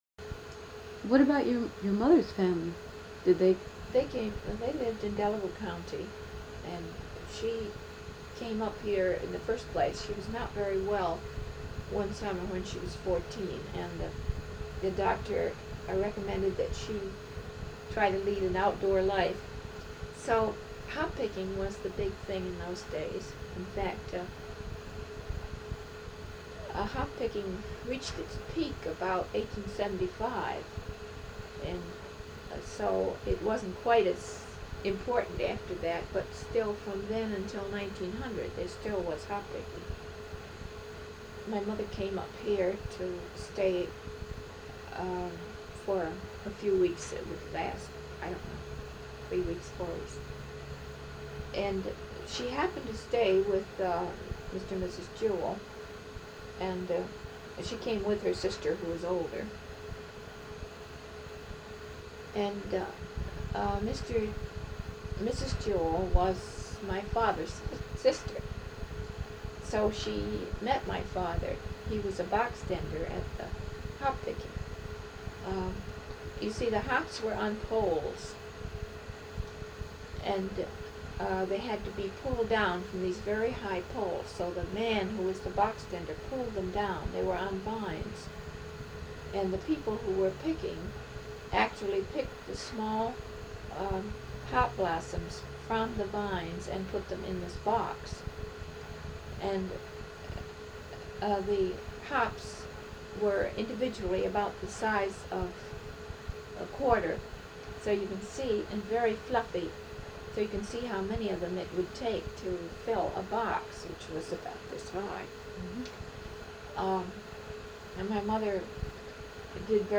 Clip created for Spring 2017 Cooperstown Graduate Program Exhibition "Hop City Pickers" at the Fenimore Art Museum Research Library.